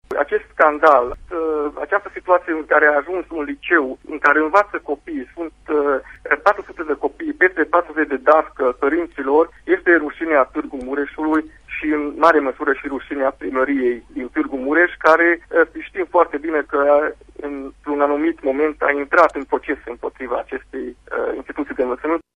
De cealaltă parte senatorul UDMR de Mureș Novak Zoltan susține că nu este vorba de acte de corupție ci doar de o rea voință din partea Primăriei Tg. Mureș: